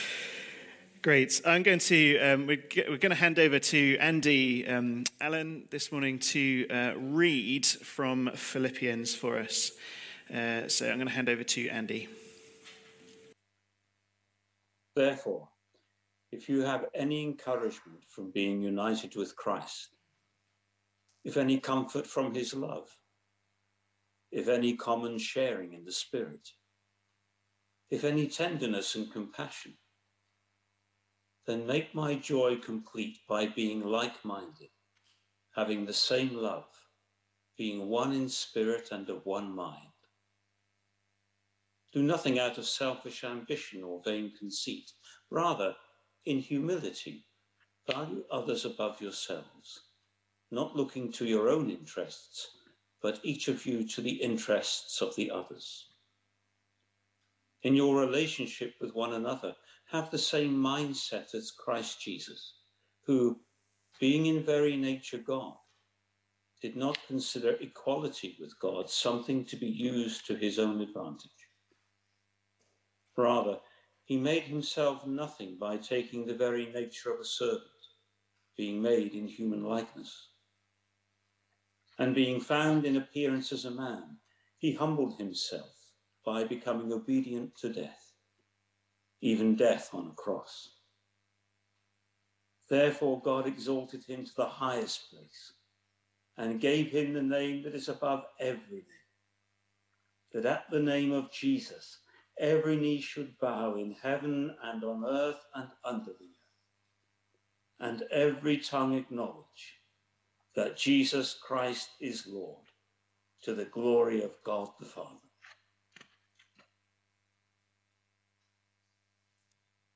A sermon preached on 2nd May, 2021, as part of our The Unexpected Journey series.